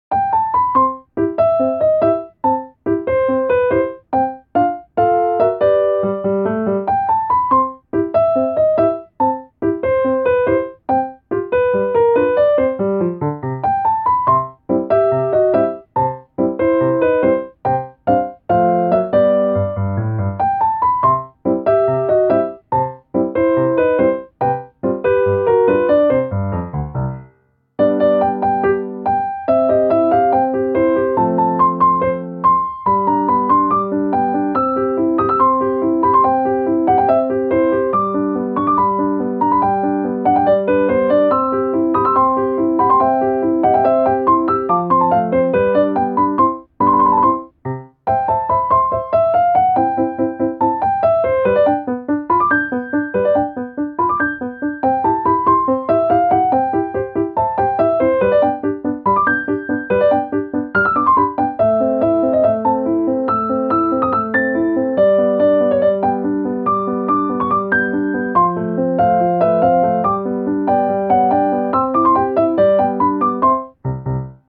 • コミカルや気軽に聞けるピアノ曲のフリー音源を公開しています。
ogg(L) - 楽しい 軽やか かわいい